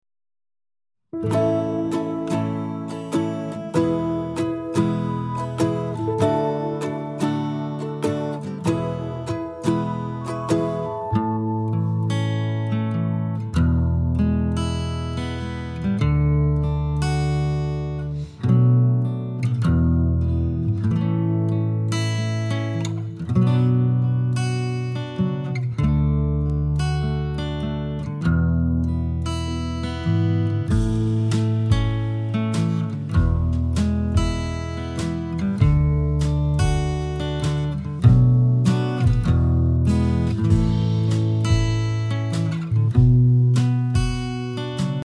Mp3 Song Download with PDF Lyrics; Mp3 Instrumental Track